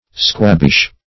Squabbish \Squab"bish\ (skw[o^]b"b[i^]sh)